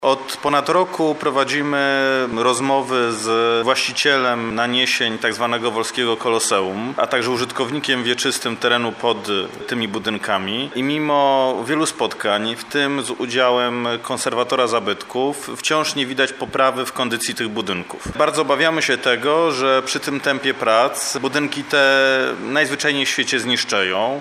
Rozmowy w tej sprawie nie przynosiły efektów – mówi burmistrz Woli Krzysztof Strzałkowski.